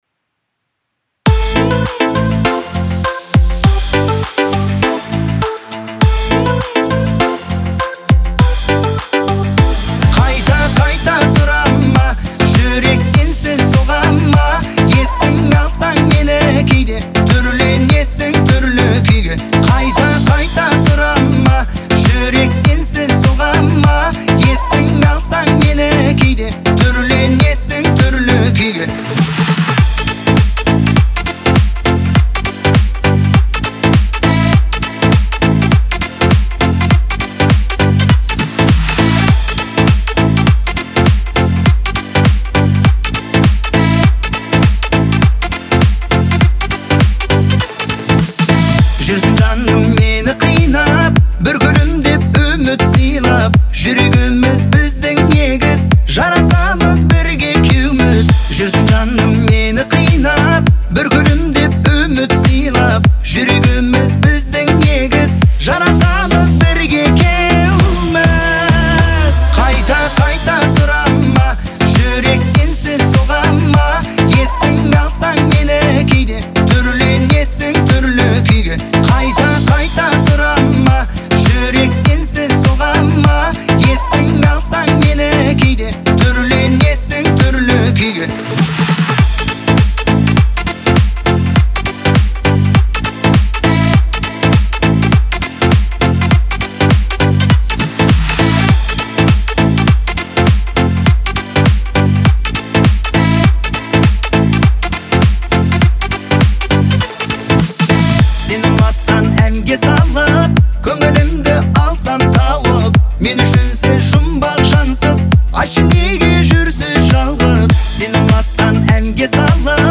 это яркая и эмоциональная песня